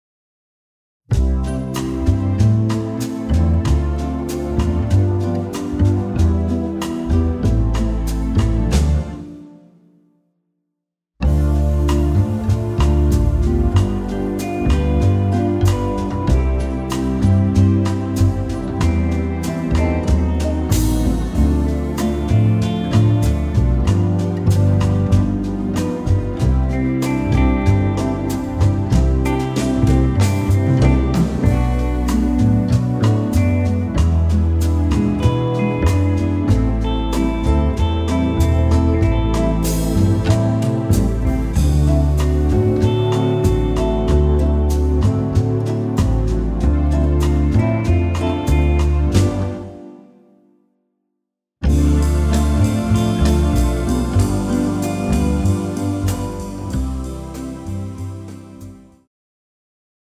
bolero style tempo 87bpm key F Male singers backing track
Latin